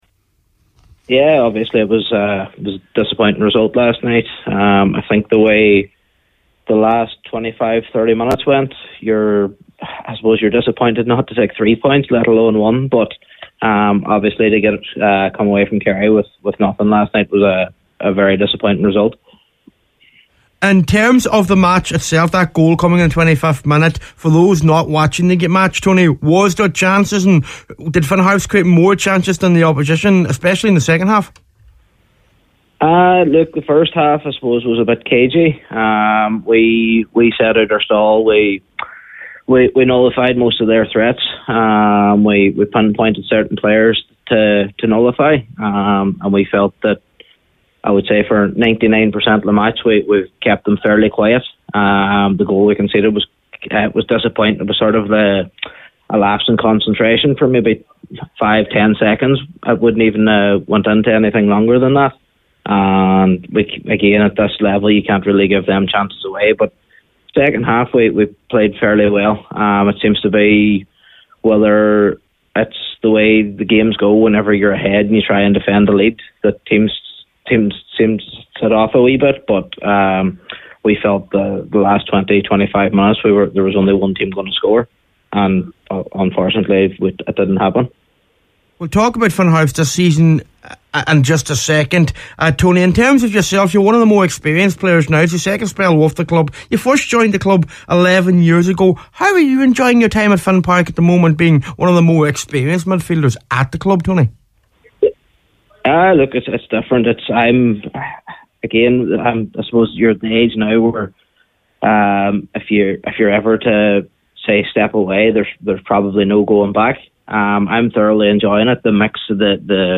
on Highland Radio Saturday Sport this afternoon to assess the game…